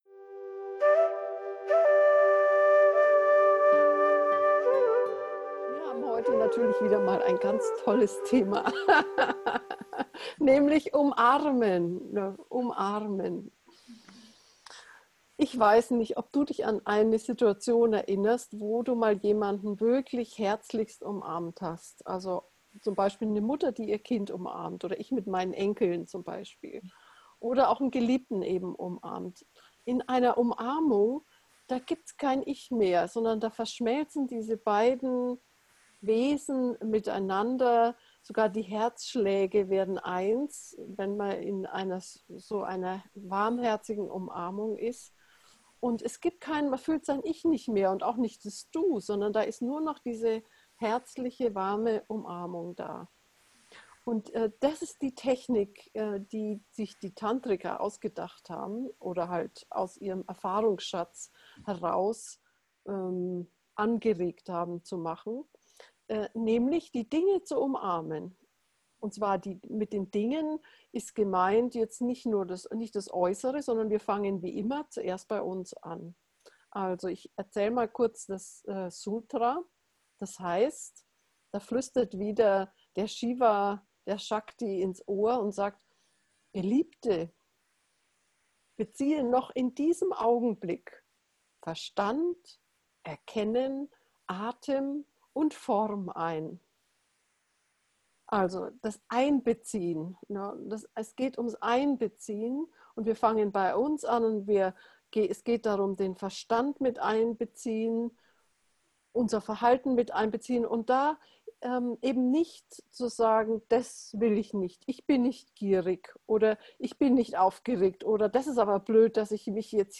Umarmen-gefuehrte-Meditation.mp3